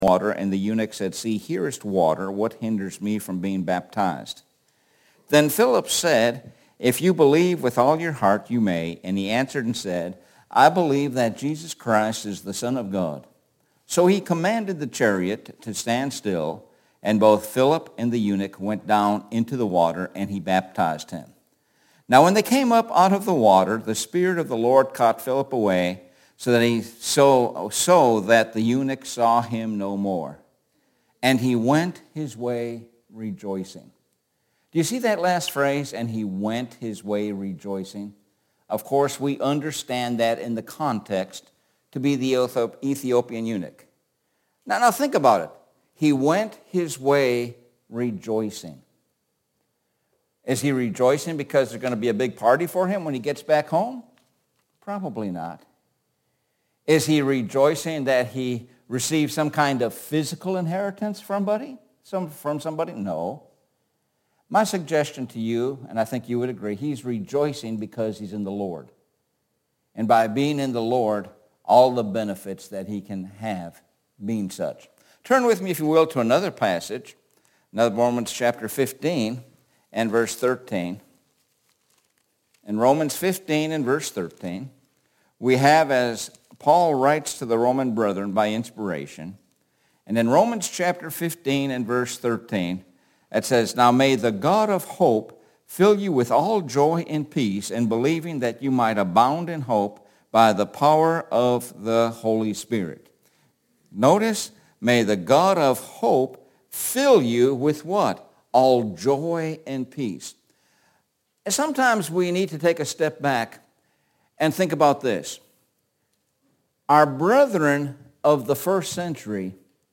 Sun PM Sermon – Rejoicing in Hope – 02.05.23 – Lakeland Hills Blvd Church of Christ